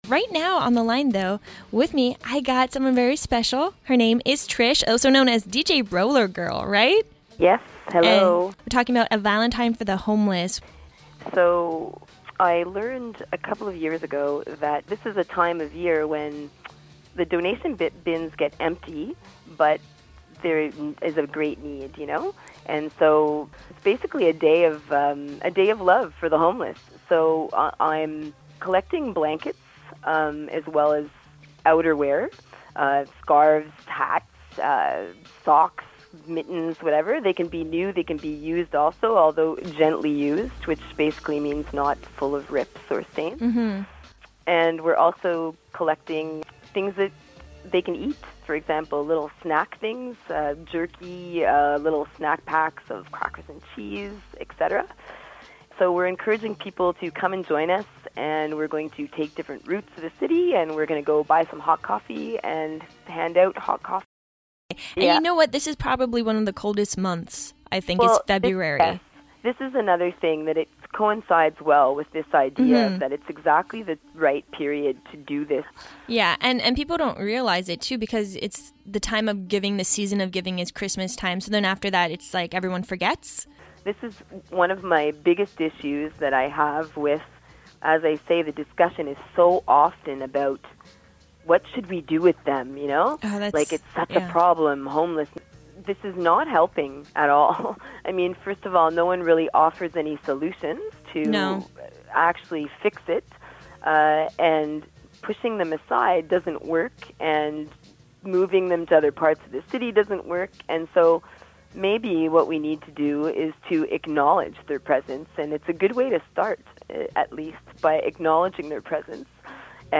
Recording Location: Montréal, Québec
This interview
0kbps Stereo